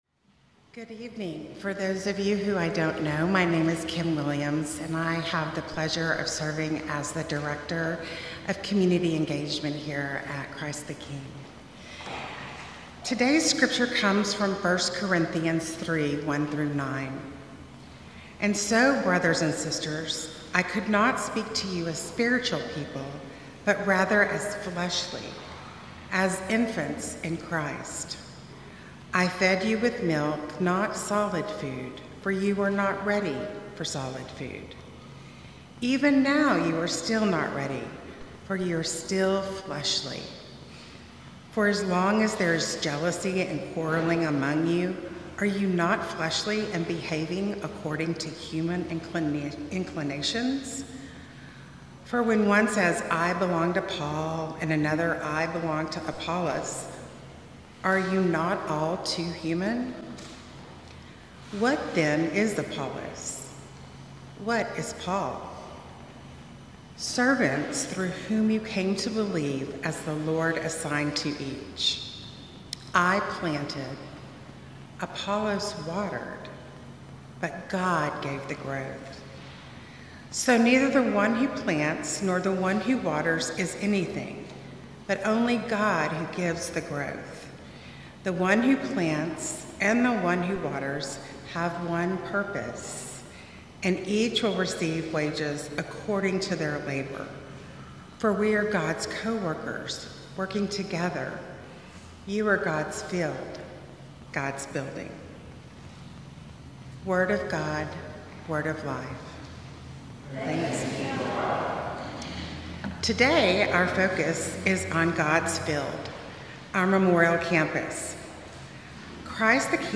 Sermon Notes
Guest Speaker